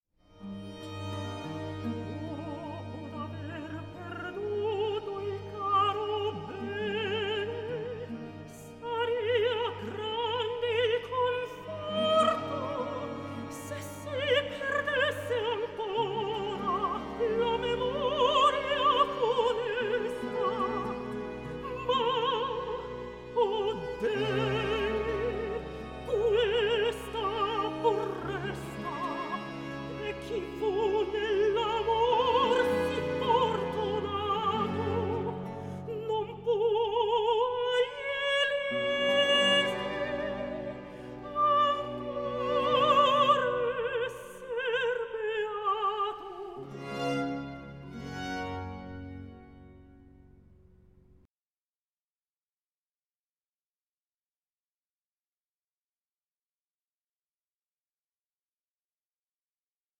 period-instrument group